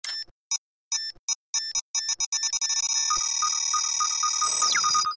Детонатор вот-вот взорвется